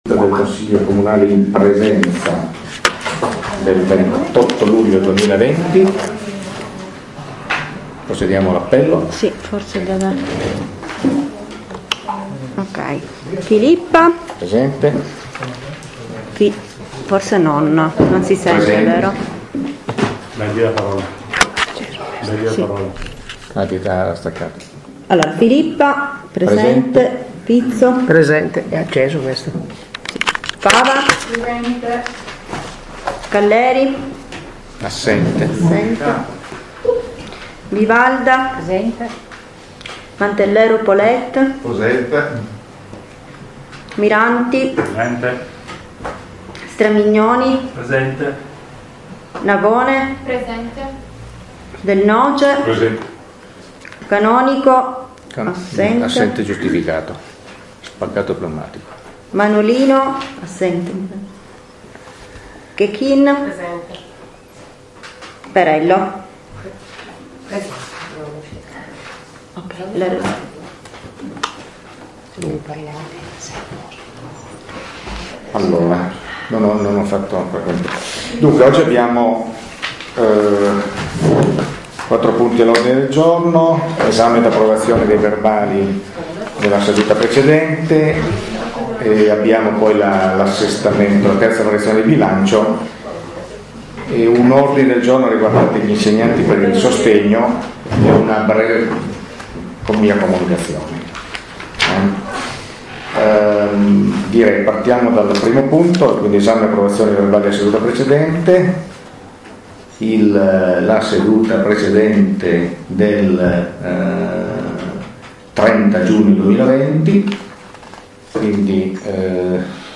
Registrazione Consiglio comunale - Comune di Pecetto Torinese